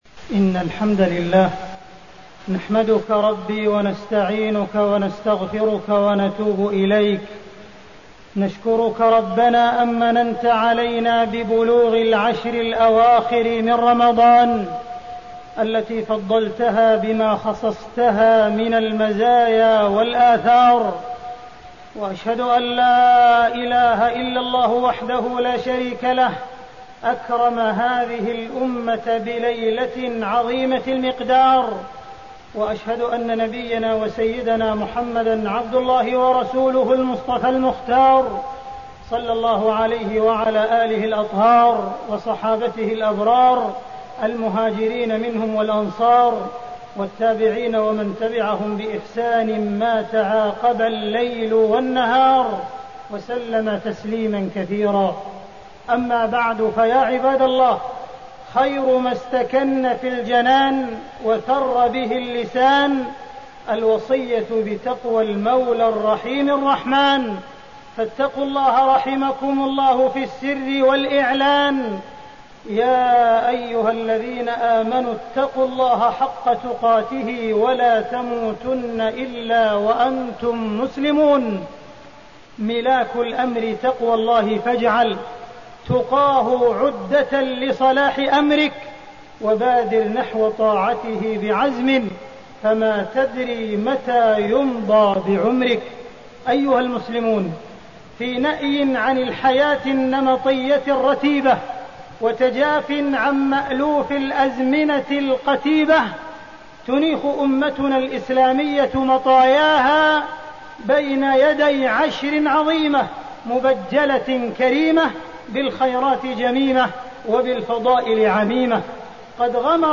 تاريخ النشر ٢١ رمضان ١٤٣٠ هـ المكان: المسجد الحرام الشيخ: معالي الشيخ أ.د. عبدالرحمن بن عبدالعزيز السديس معالي الشيخ أ.د. عبدالرحمن بن عبدالعزيز السديس العشر الأواخر من رمضان